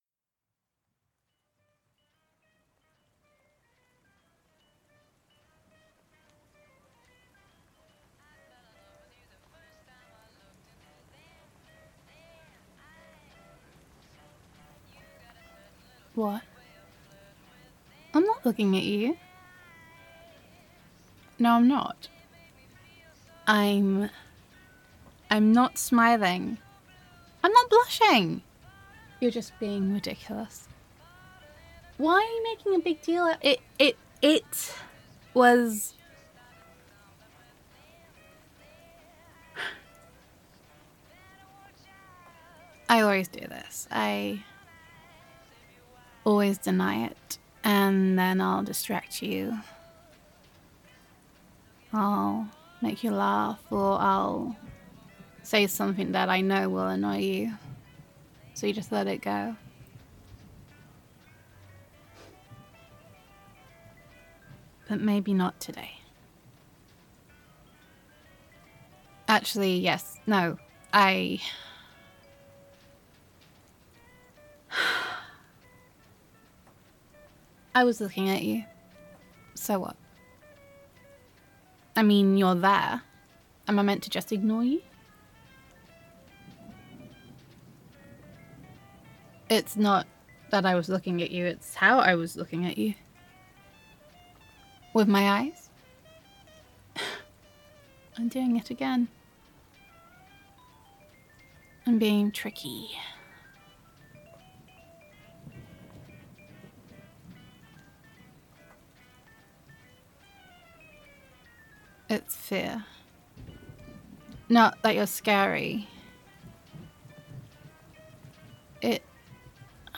[F4A] Looking at You the Way You Look at Me